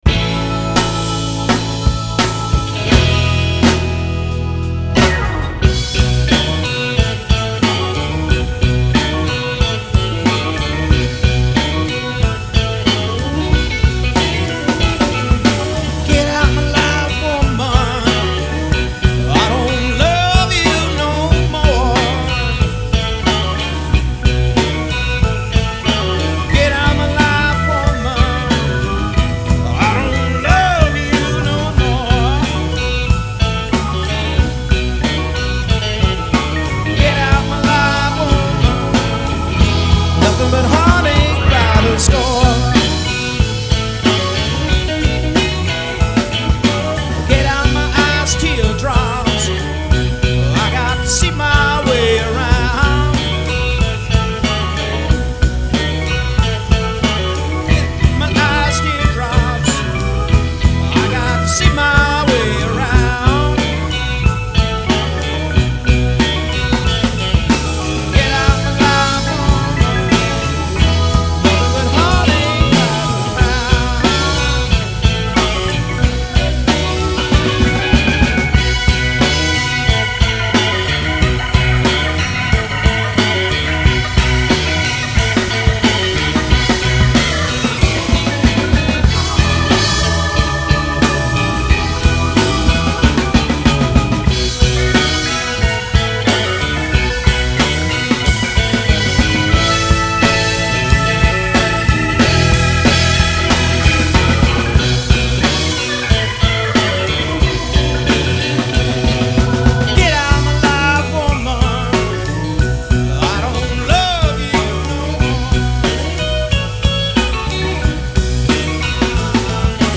Guitar / Voix
Claviers
Batterie
Bass